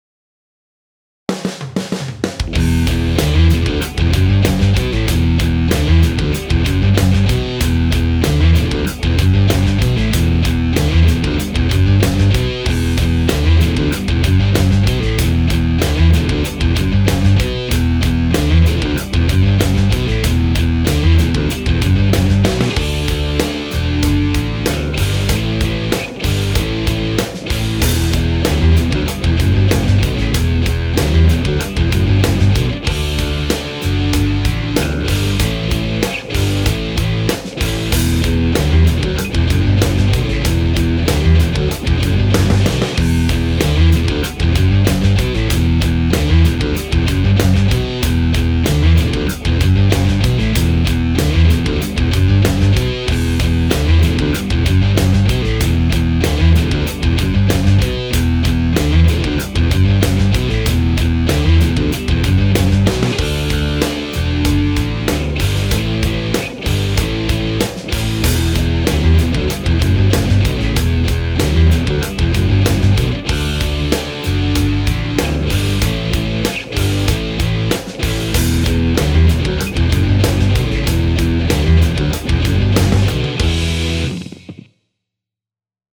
音楽ジャンル： メタル
楽曲の曲調： HARD
シチュエーション： 激しい